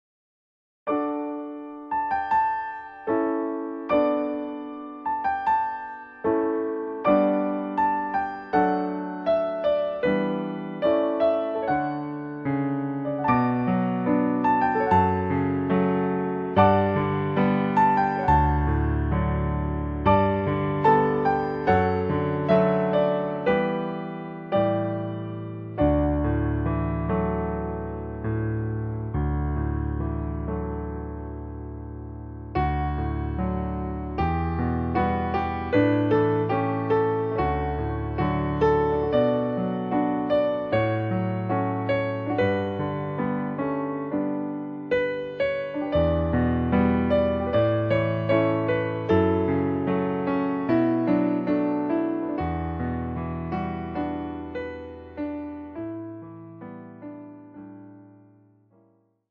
〜 透明感あふれる3rdピアノソロアルバム。
3rdピアノソロアルバム。